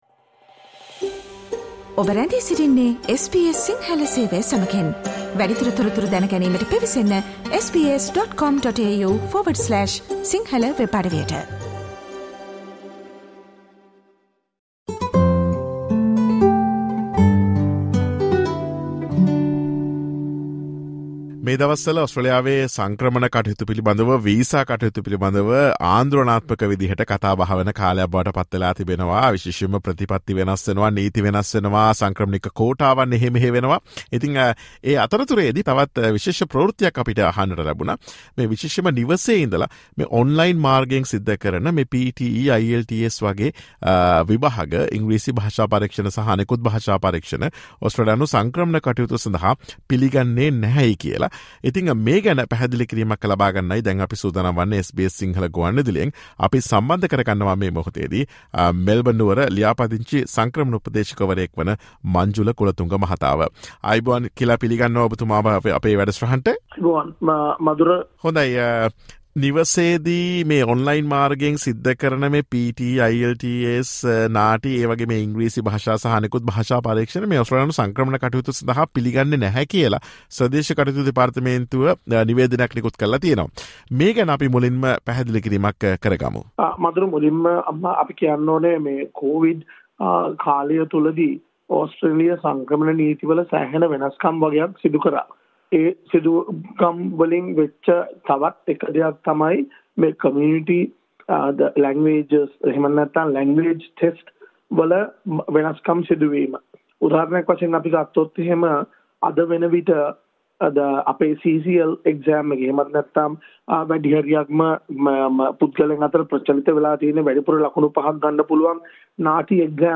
Listen to SBS Sinhala Radio's interview on the latest changes to NAATI and IELTS, PTE Online exams for Australian migration purposes.